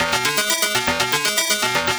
CS_FMArp C_120-E.wav